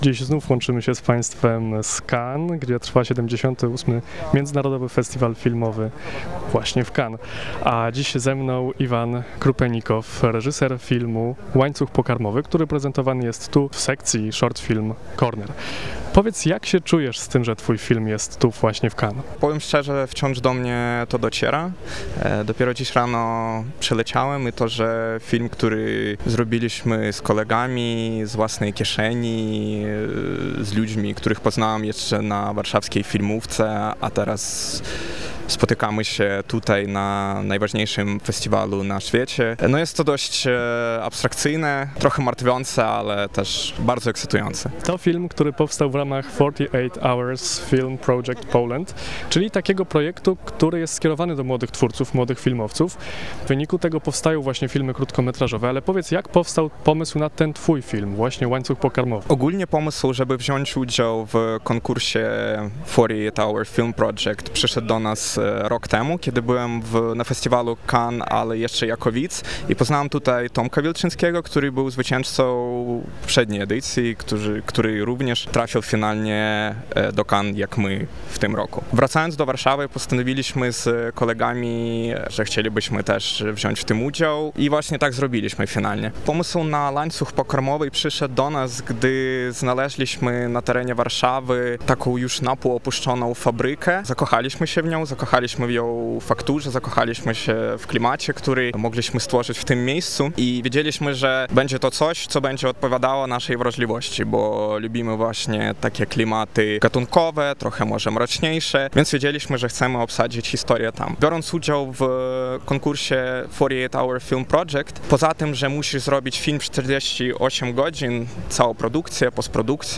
Studio Cannes: rozmowa